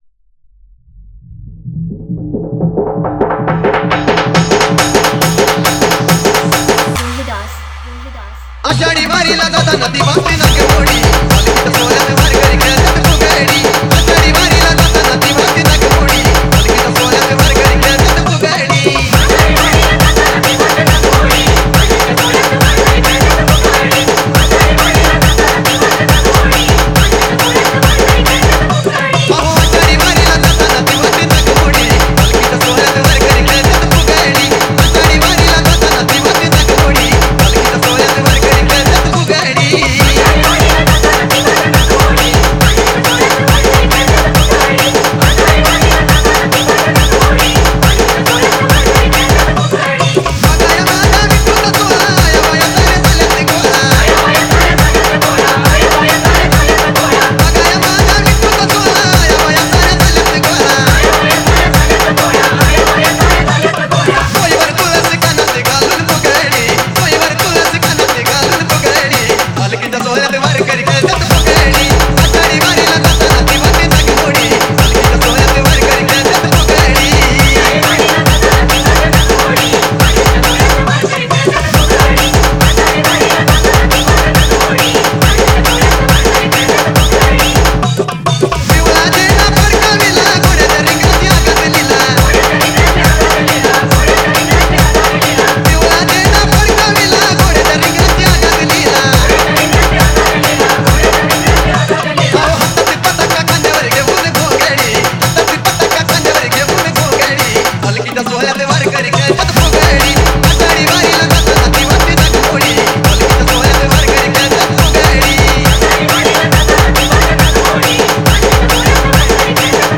• Category:Marathi Single